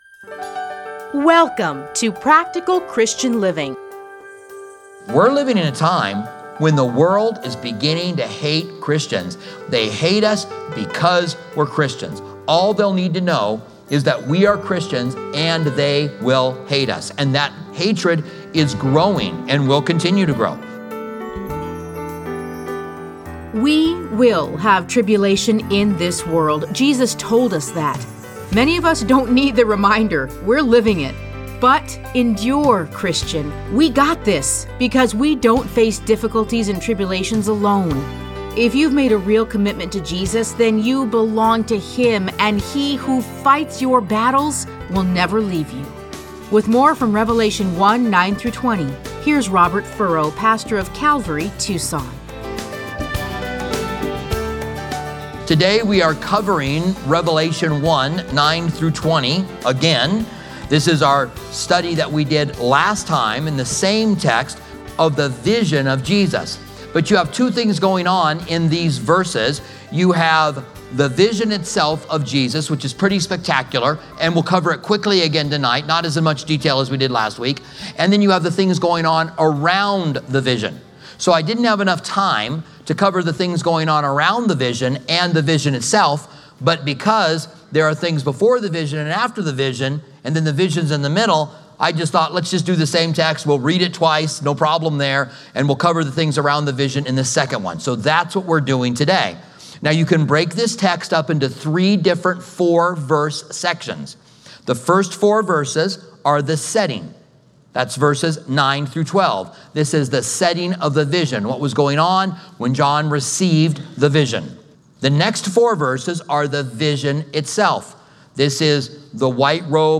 Listen to a teaching from Revelation 1:9-20.